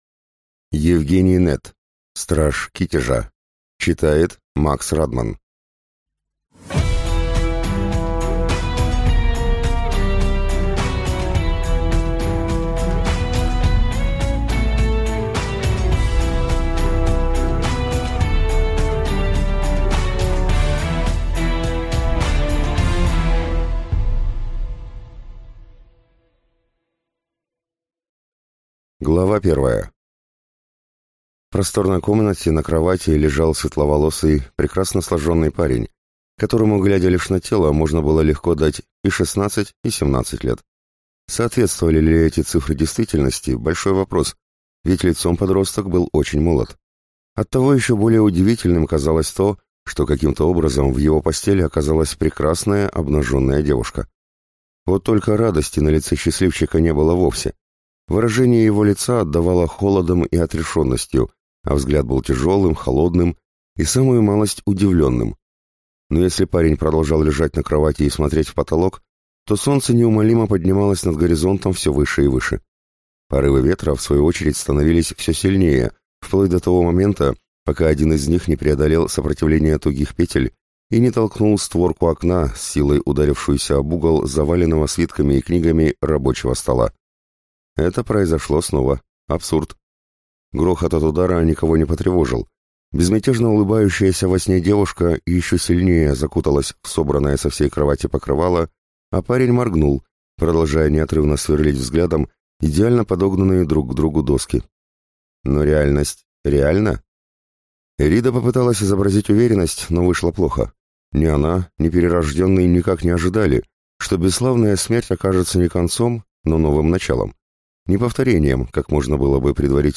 Аудиокнига Страж Китежа | Библиотека аудиокниг